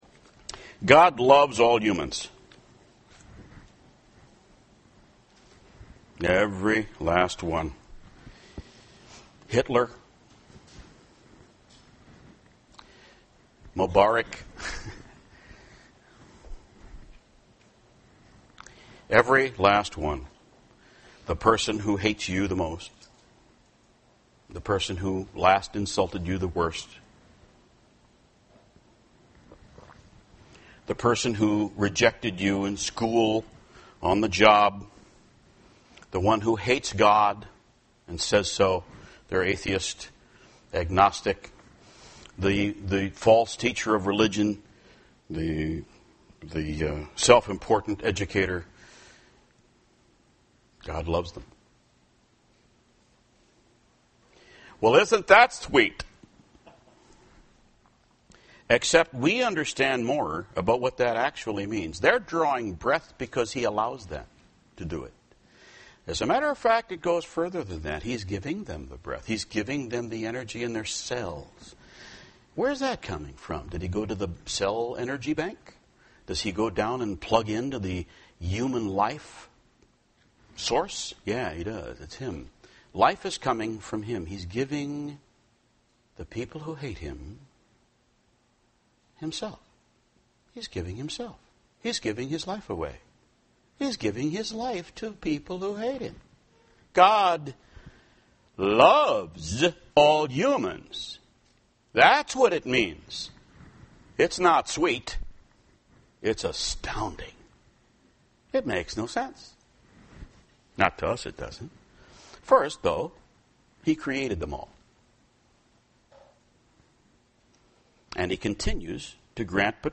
UCG Sermon Notes Sermon “Love for All Humans” Intro: God Loves all Humans.